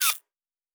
Servo Small 8_2.wav